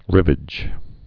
(rĭvĭj)